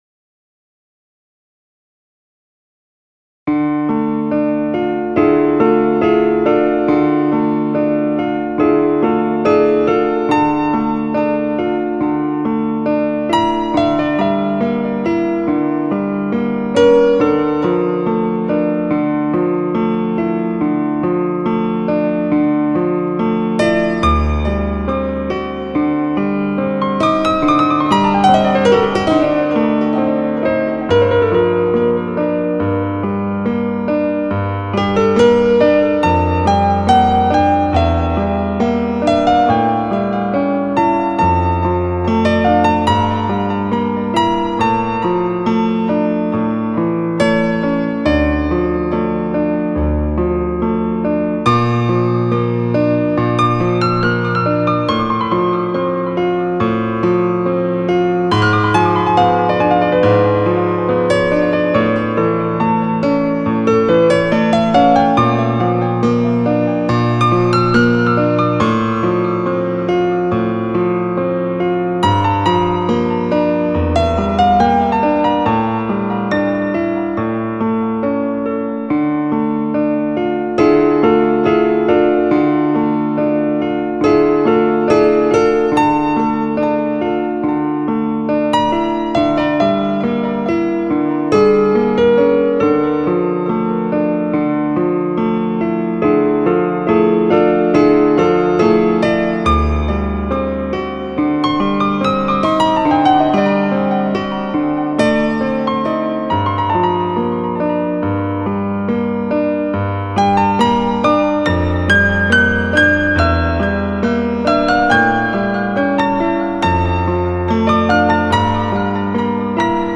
А музыка грустная.